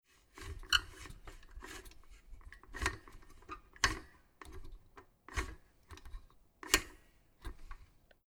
Toaster Star Electric Mod. 75000
Drehen
22859_Drehen.mp3